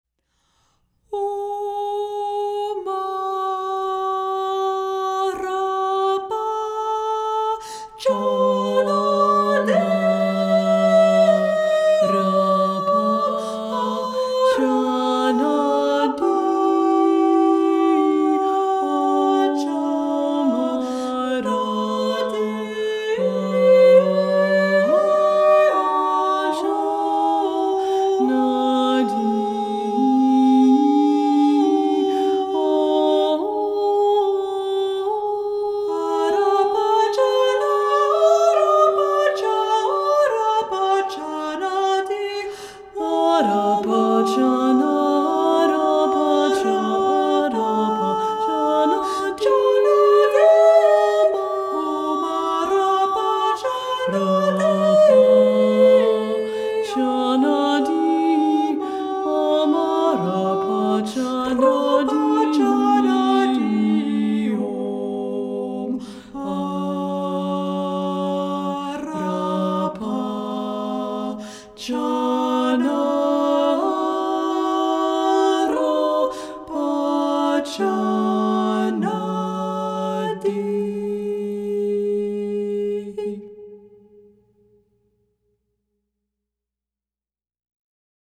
Buddhist Music in the Western Art Tradition
Devotional music to the Buddhas and Bodhisattvas